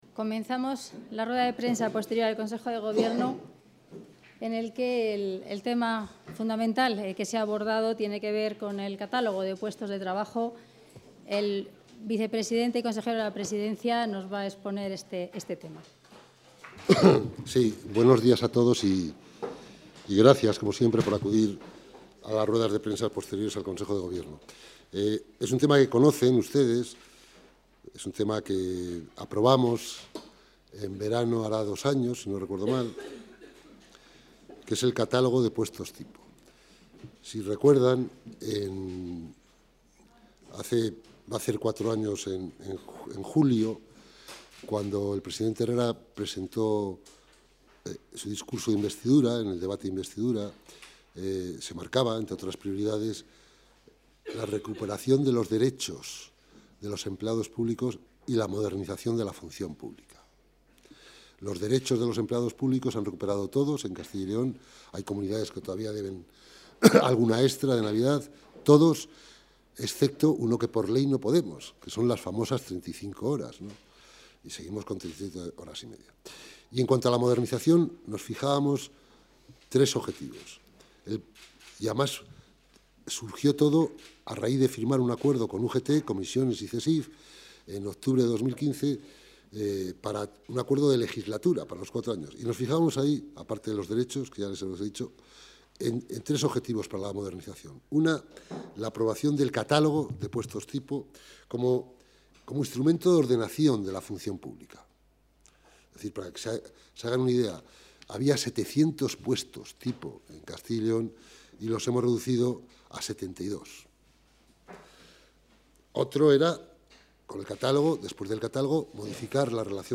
Rueda de prensa tras el Consejo de Gobierno.